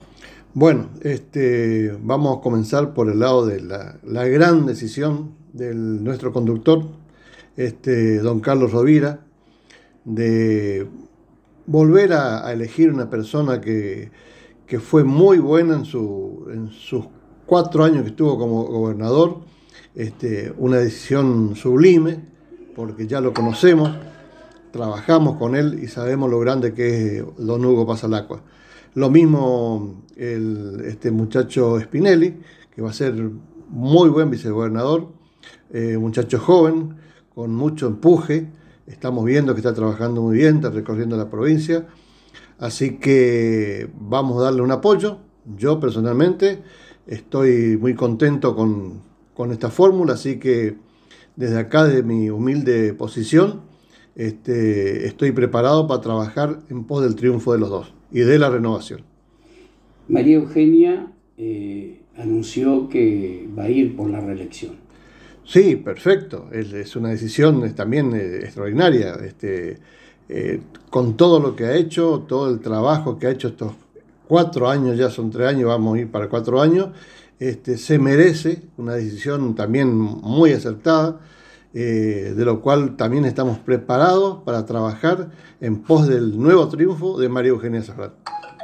El Secretario de Desarrollo Social de Apóstoles Alberto «Nene» Sotelo en diálogo exclusivo con la ANG manifestó su agrado por la gran decisión del Conductor de la Renovación al elegir a Hugo Passalacqua como candidato a Gobernador ya que cuando fue Gobernador gobernó con mucha sabiduría y sensibilidad social y destacó la elección de Lucas Spinelli ya que demostró ser un joven capaz y decidido a trabajar por el bienestar de los misioneros.